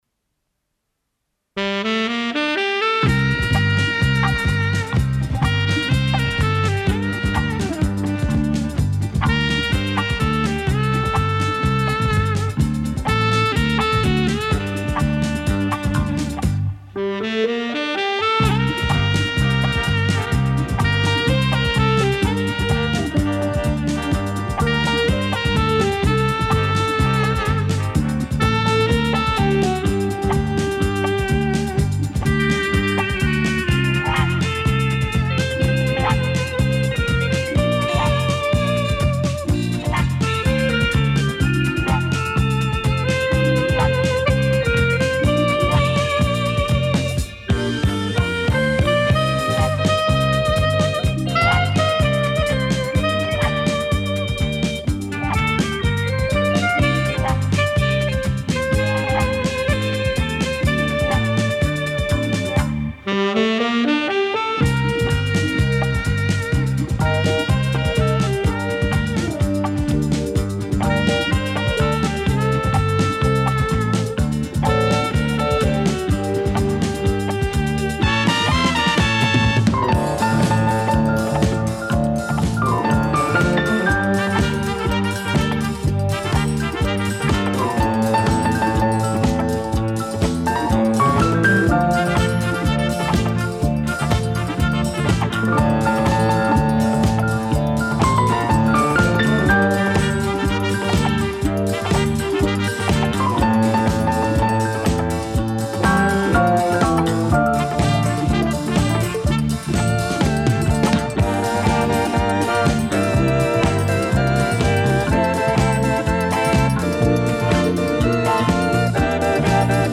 Любителям инструментальной музыки несколько эстрадных пьес с катушки.